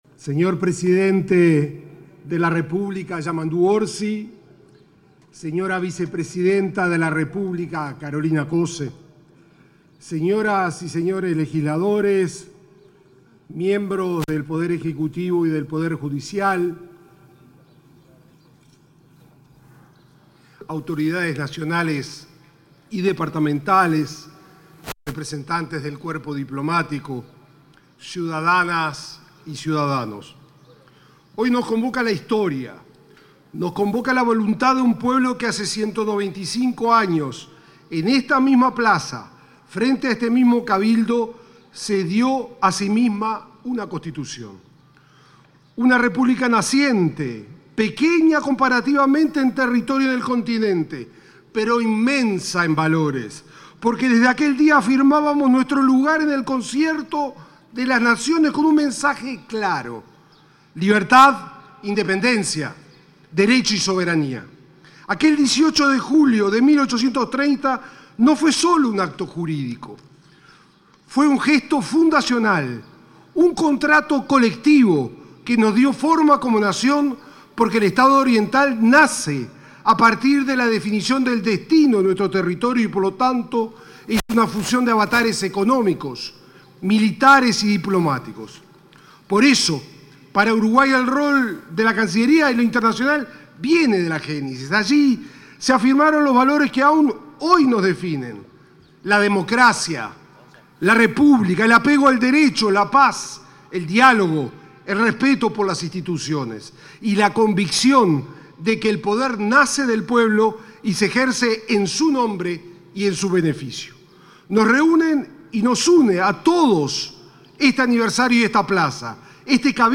Palabras del canciller de la República, Mario Lubetkin 18/07/2025 Compartir Facebook X Copiar enlace WhatsApp LinkedIn Palabras del ministro de Relaciones Exteriores, Mario Lubetkin, como principal orador del acto de conmemoración del 195.° aniversario de la Jura de la Constitución.